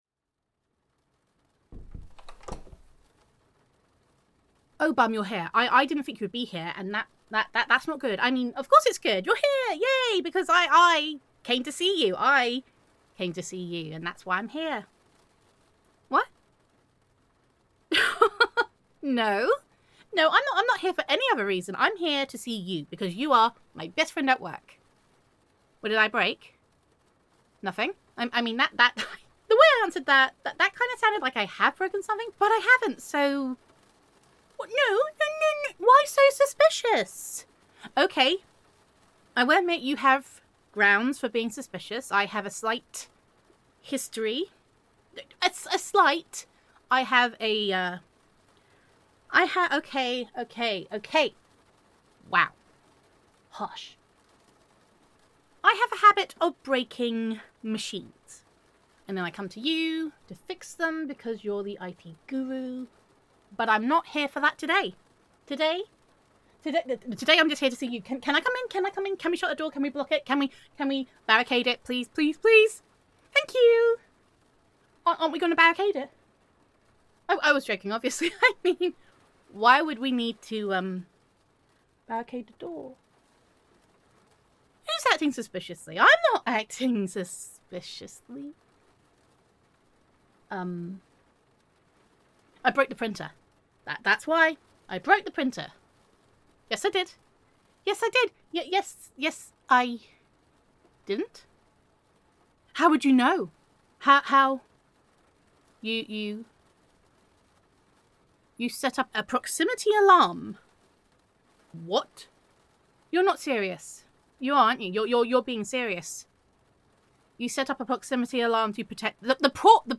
[F4A]
[Co-worker Roleplay]